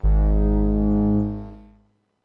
描述：通过Behringer调音台采样到MPC 1000。它听起来一点也不像风的部分，因此被称为Broken Wind。
Tag: 模拟 多样品 合成器 虚拟模拟